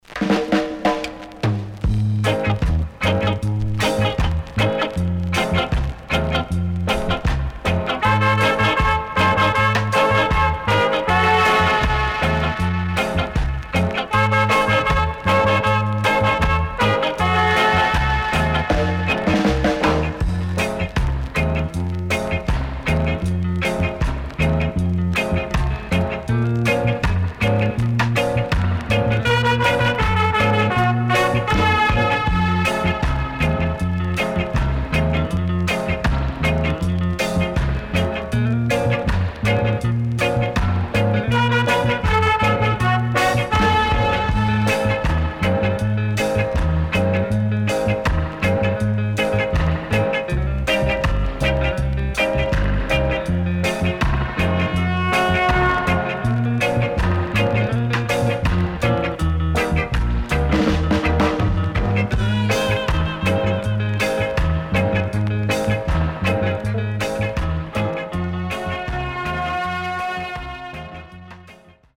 CONDITION SIDE A:VG(OK)〜VG+
Nice Female Vocal Soulful Cover.Recommend!!
SIDE A:所々チリノイズがあり、少しプチノイズ入ります。